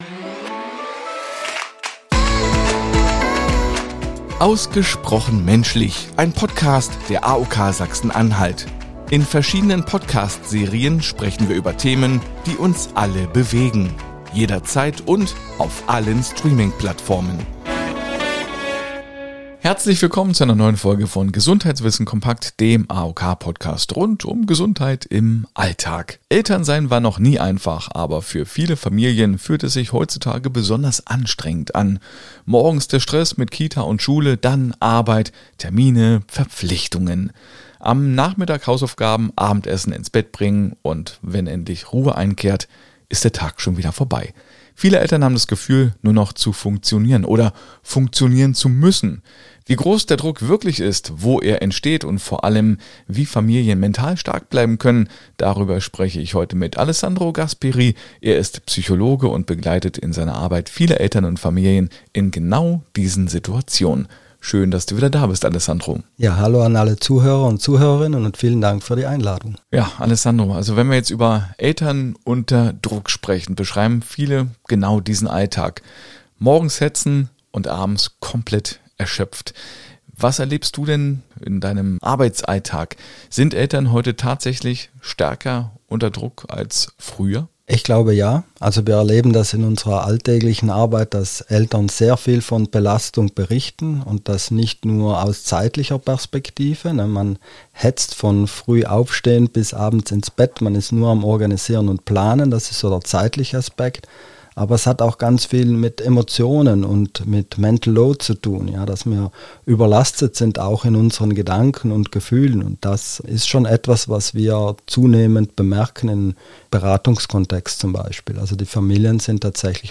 Er ist Psychologe und begleitet in seiner Arbeit viele Eltern und Familien in genau diesen Situationen.